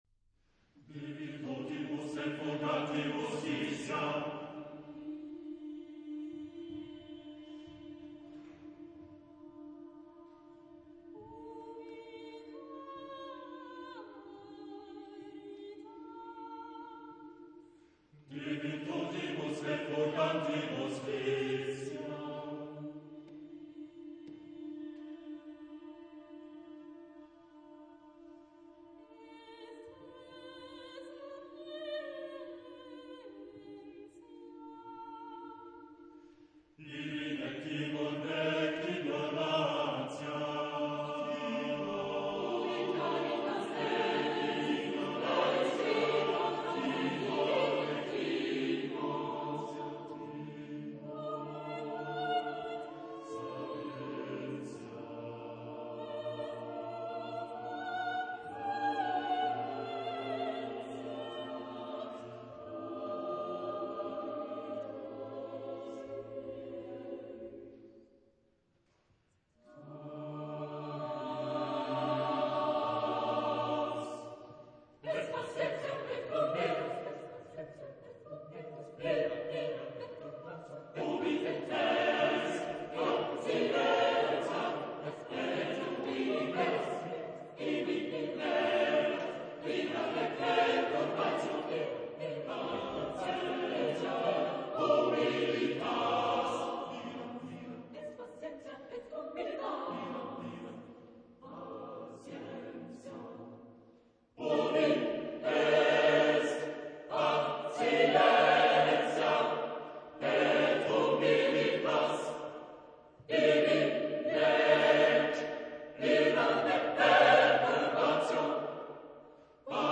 Genre-Style-Form: Choir
Mood of the piece: energetic
Type of Choir: SSATTBB  (5 mixed voices )
Tonality: D flat minor